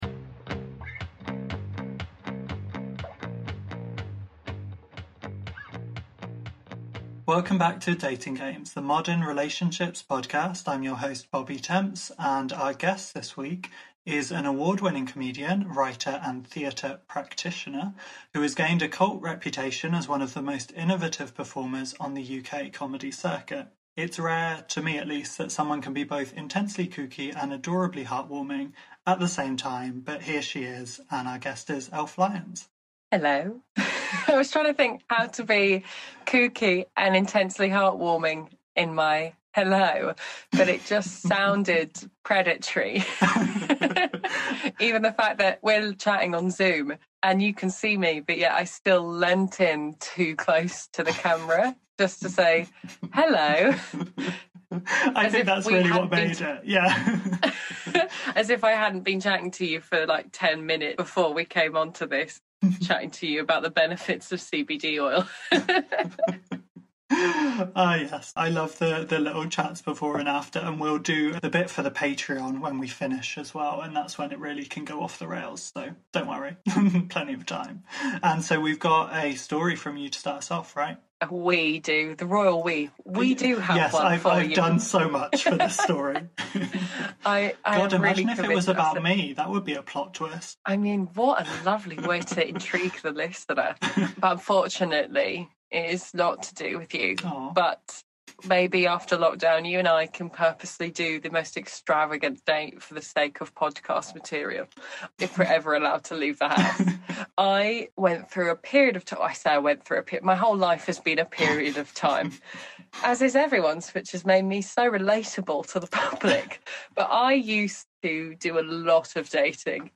This week Elf Lyons Zooms in for a lighthearted chat that also gets quite moving in the middle as we talk family, polyamory and one very intense date.